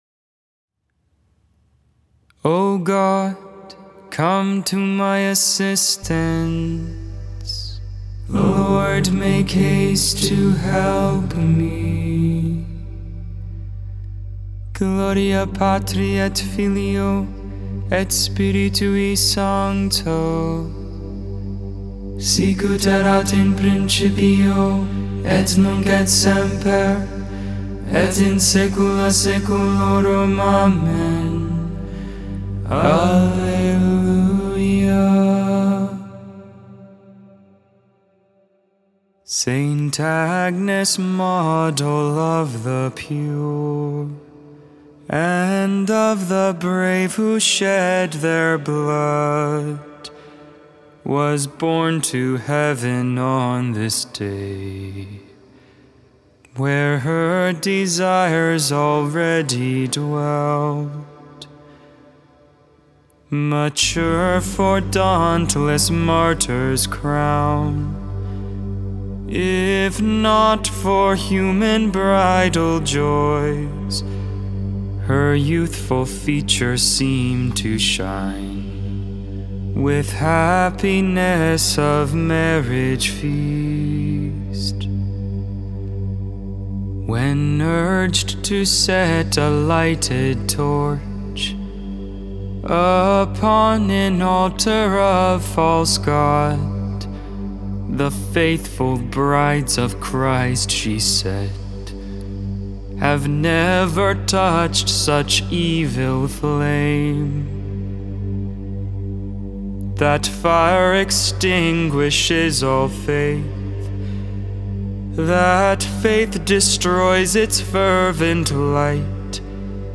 1.21.22 Lauds, Friday Morning Prayer